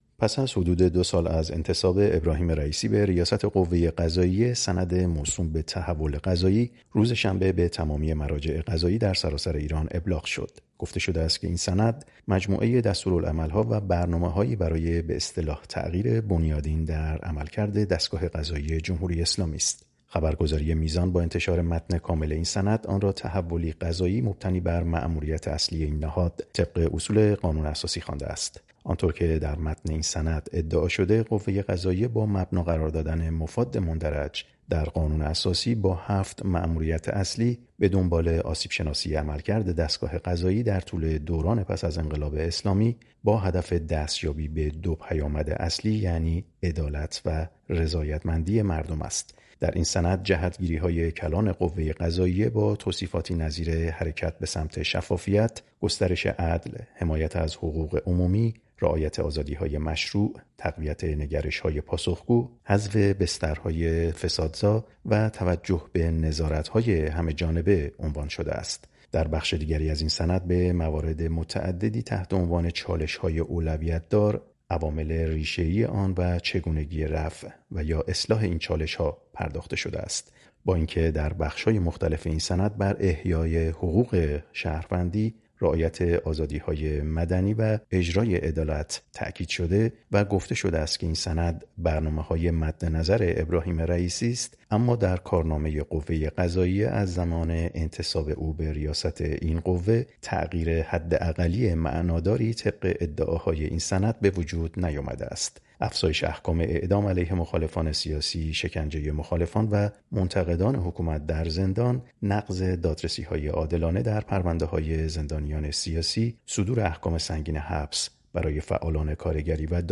گزارش می دهد